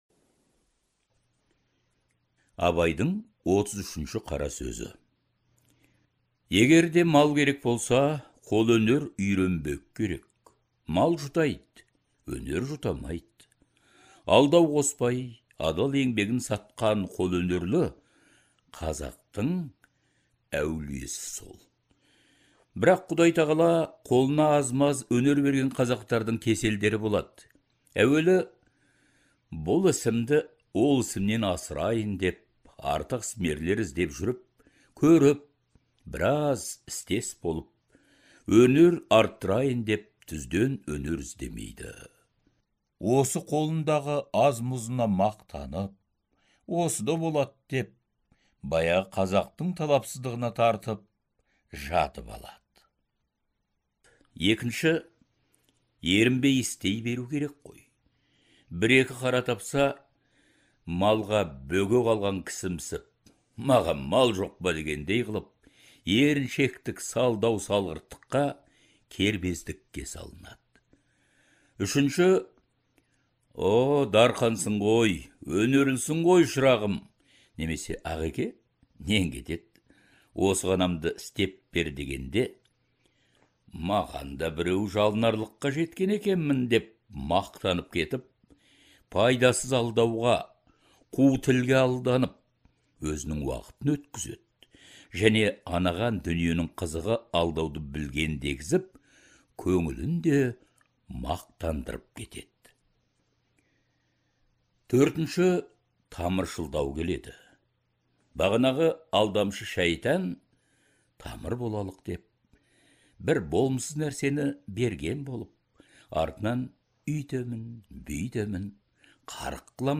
Даналық көсемсөздердің аудио нұсқасы Әдебиет институтының студиясында жазылып алынған.